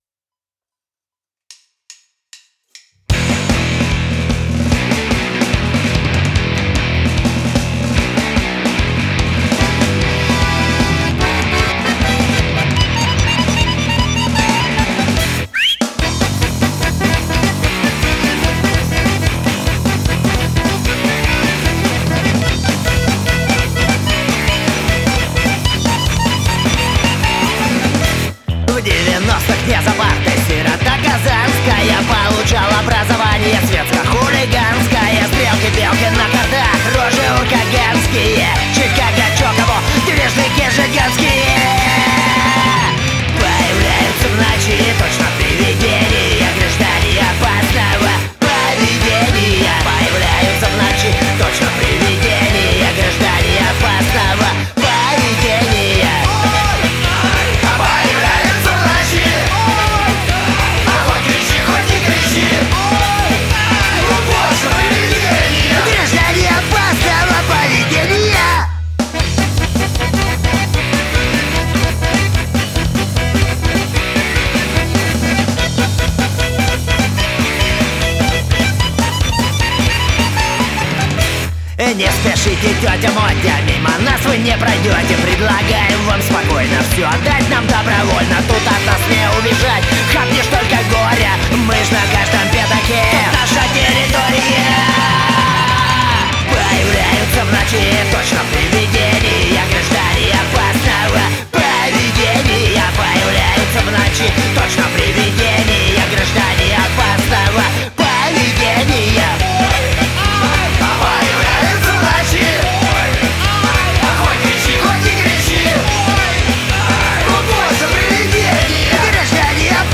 Попинайте чтоли за панк-рок?
Демка сведения сибирских панков.